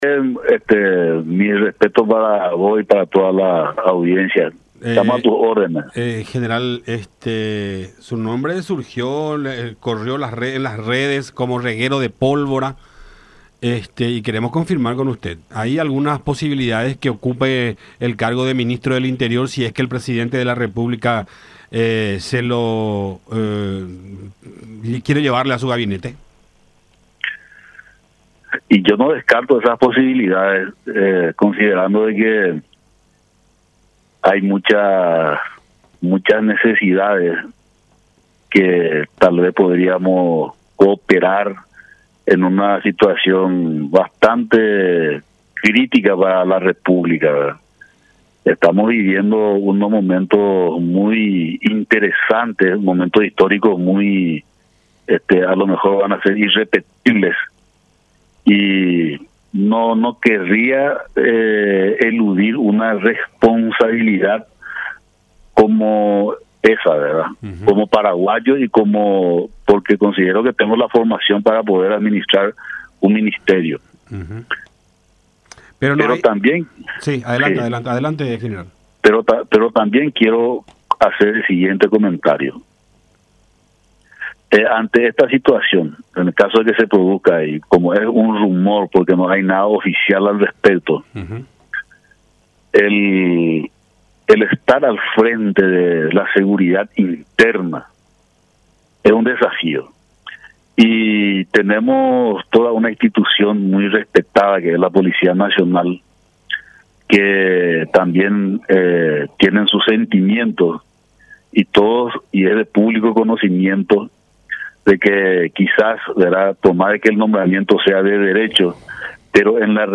en contacto con La Unión R800 AM